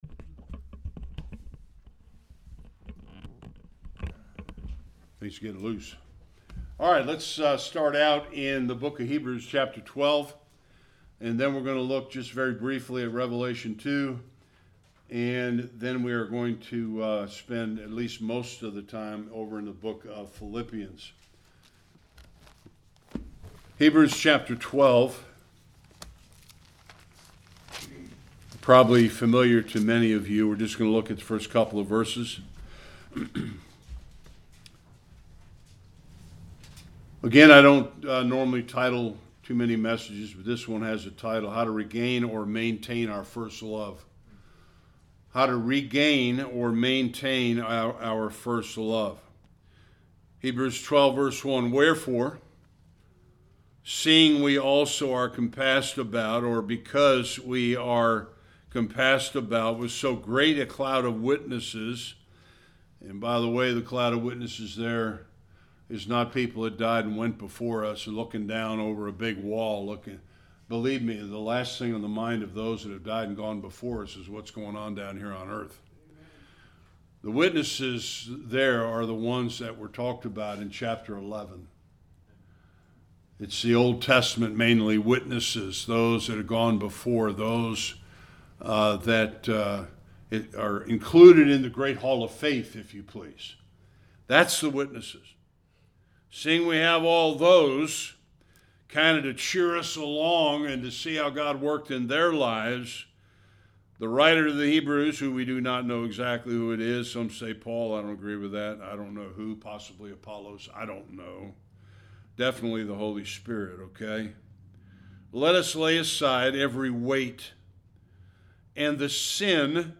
Various Passages Service Type: Sunday Worship 5 principles to keep in mind as we run the race in our pursuit of Christlikeness.